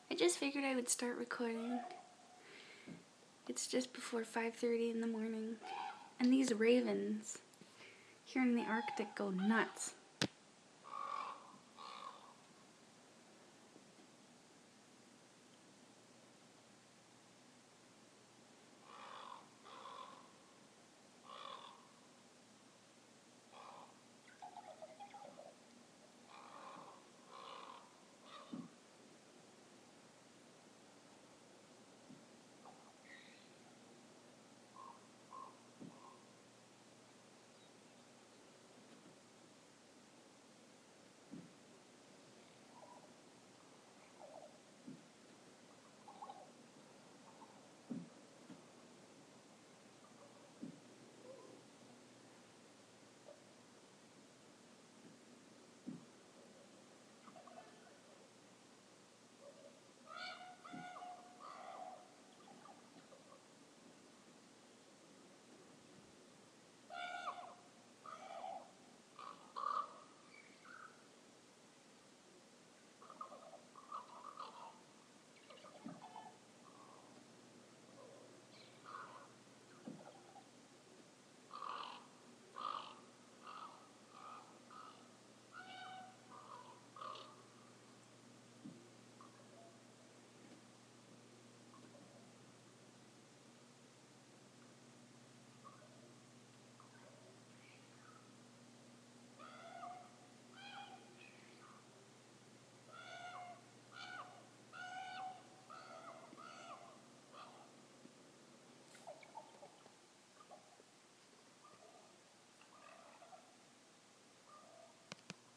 listening to ravens